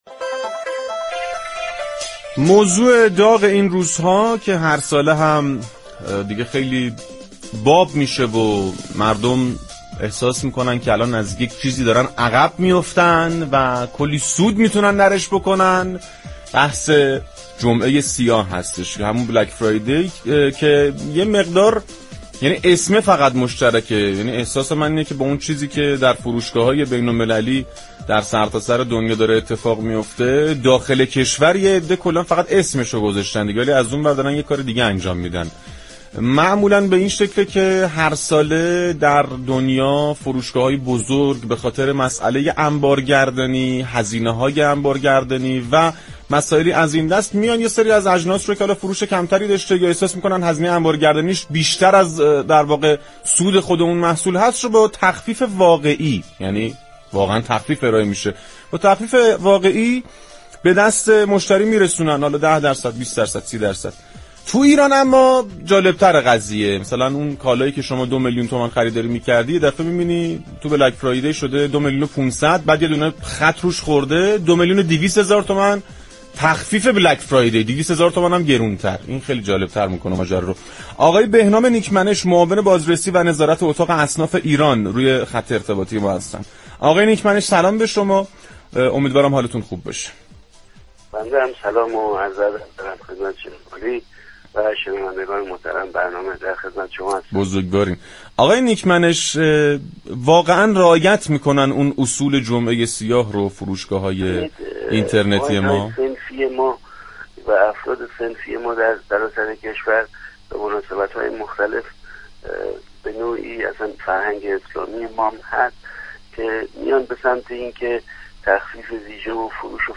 گفت‌وگو با رادیو تهران